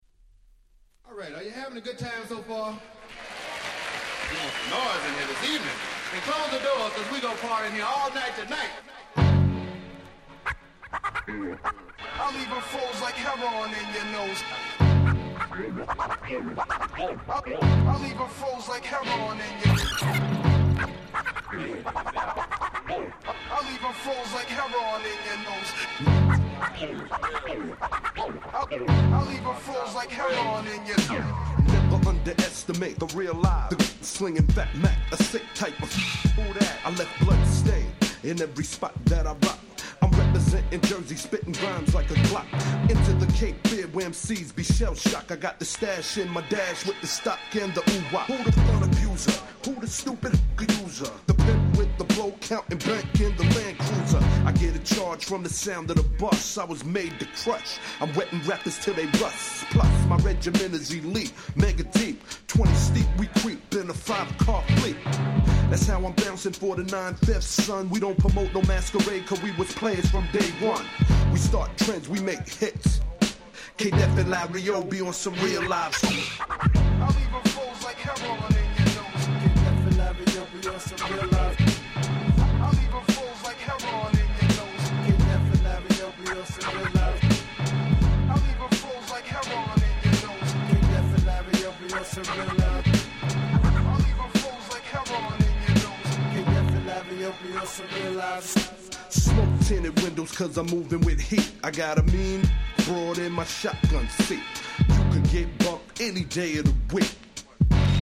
96' Smash Hit Hip Hop !!